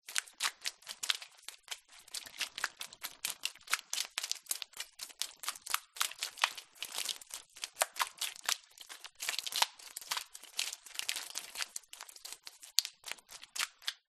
Шорох рыбы, ползущей по полу